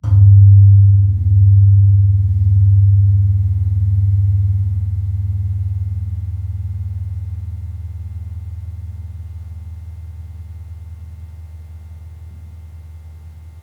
Gong-G1-f.wav